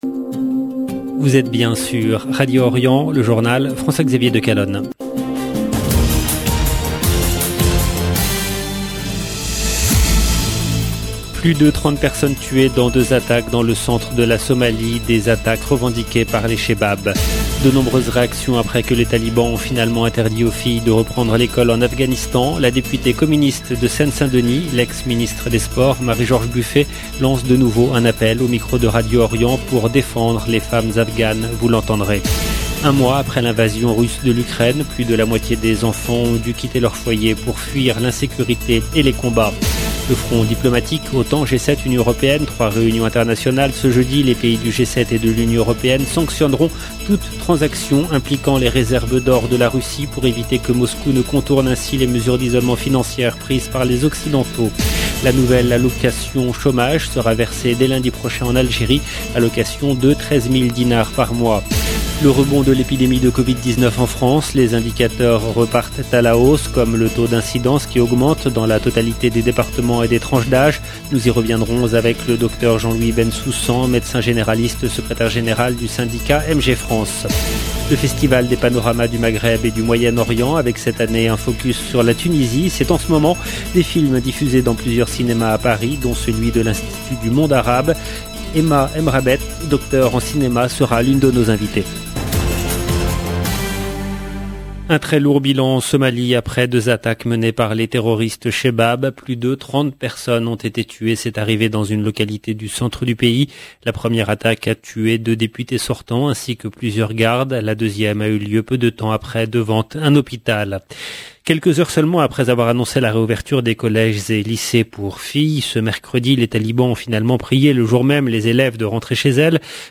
LE JOURNAL DU SOIR EN LANGUE FRANCAISE DU 24/3/2022
La député PCF de Seine-Saint-Denis, l’ex ministre des sports Marie-George Buffet lance de nouveau un appel au micro de Radio Orient pour défendre les femmes afghanes. Vous l'entendrez. 1 mois après l’invasion russe de l’Ukraine, plus de la moitié des enfants en Ukraine ont dû quitter leur foyer pour fuir l'insécurité et les combats.